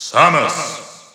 The announcer saying Samus' name in English and Japanese releases of Super Smash Bros. 4 and Super Smash Bros. Ultimate.
Samus_English_Announcer_SSB4-SSBU.wav